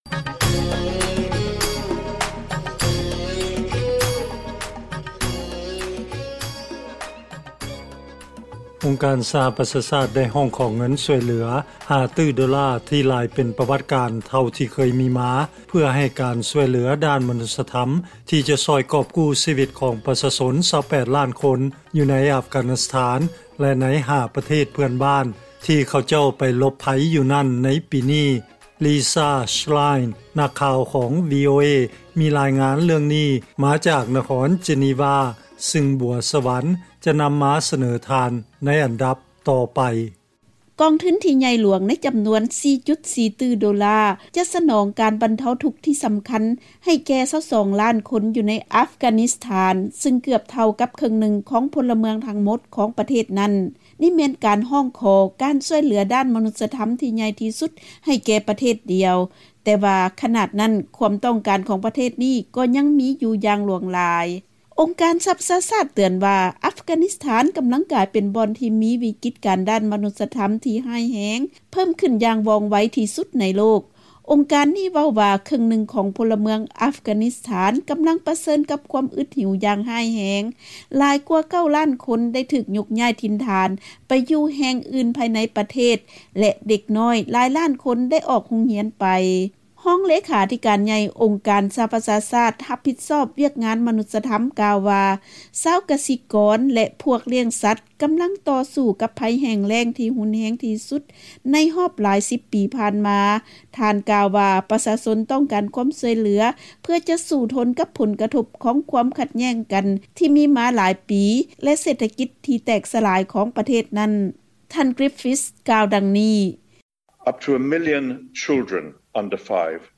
ເຊີນຟັງລາຍງານກ່ຽວກັບອົງການ ສປຊ ຮ້ອງຂໍຄວາມຊ່ວຍເຫລືອຈາກສາກົນເພື່ອໄປຊ່ວຍອັຟການິສຖານ